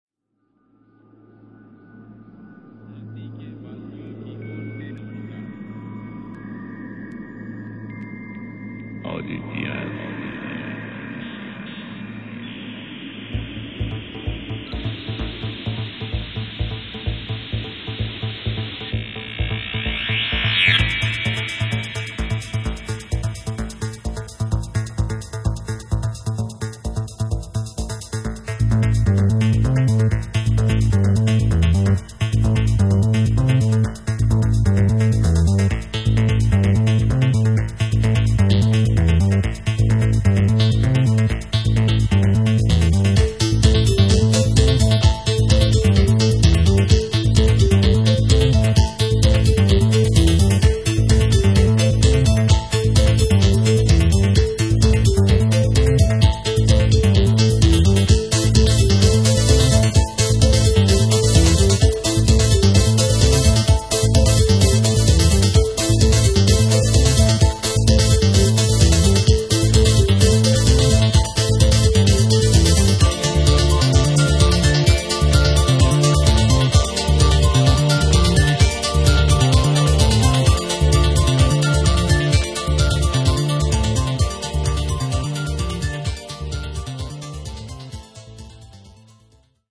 Melodische Elektronik vom Feinsten.
Harmonien und Sequenzen zum Träumen.